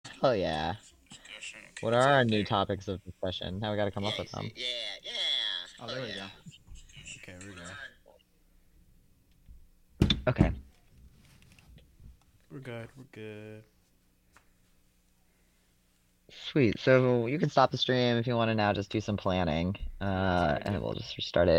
Live from the Catskill Clubhouse.